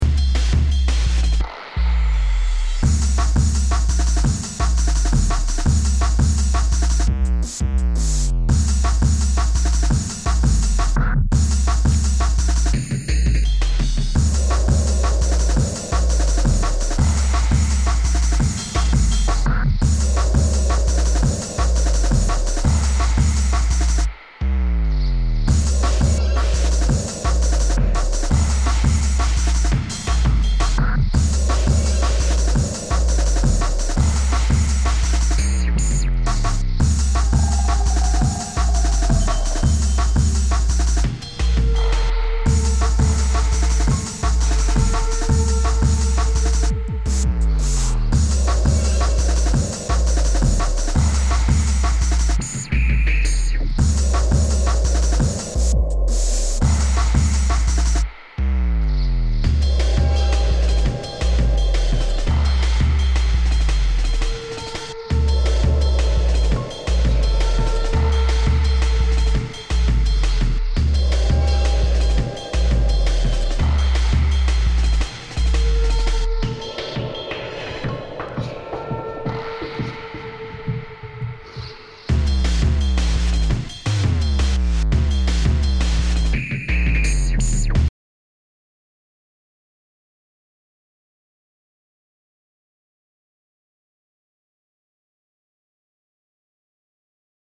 Jungle/Drum n Bass
Drum & Bass